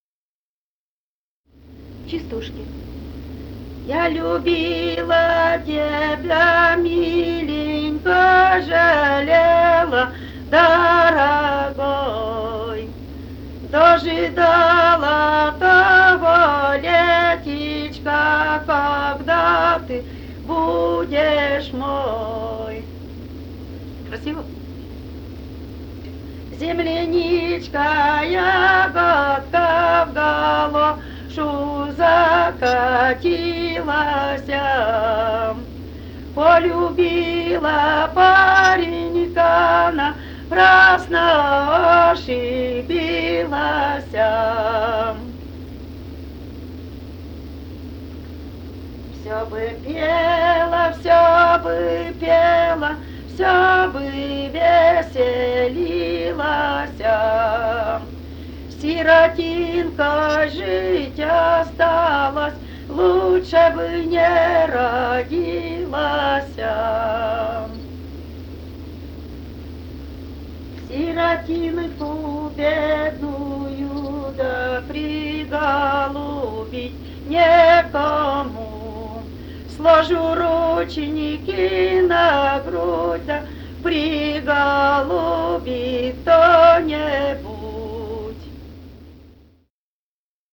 полевые материалы
«Я любила тебя, миленькой» (частушки).
Пермский край, г. Оса, 1968 г. И1074-09